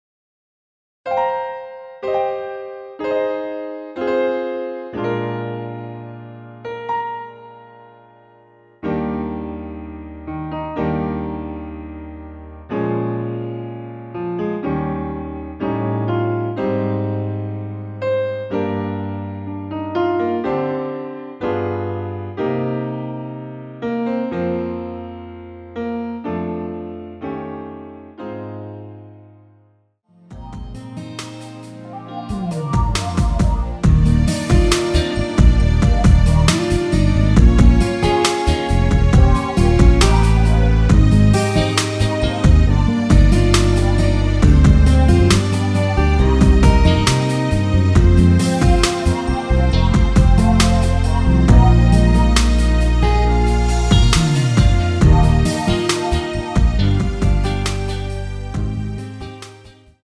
원키에서(-2)내린 MR입니다.
Eb
앞부분30초, 뒷부분30초씩 편집해서 올려 드리고 있습니다.